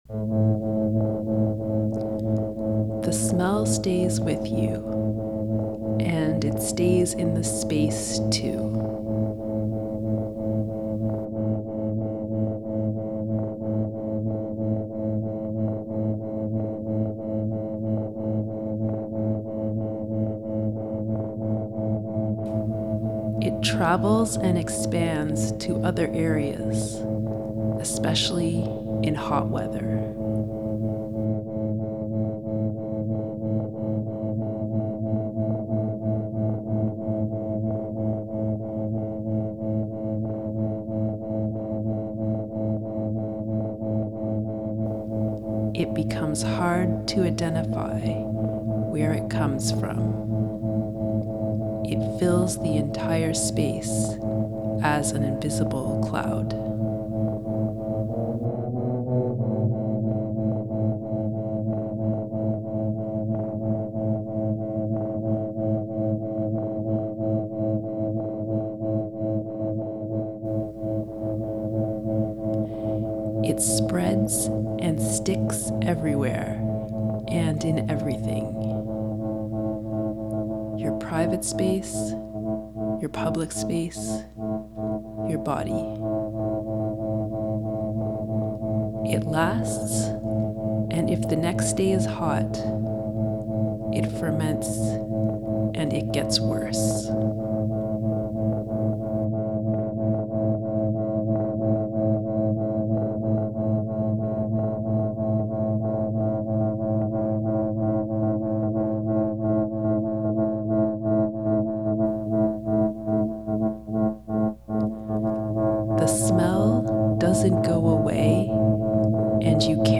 When the visual documentation of the performance didn’t turn out as planned I decided to use only textual descriptions, sound, some notes from my research and a few images of reactions from the audience to depict the performance.